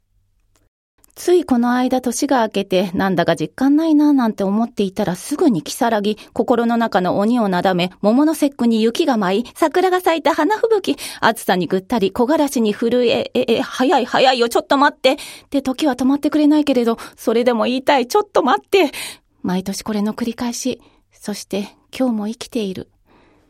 ボイスサンプル
朗読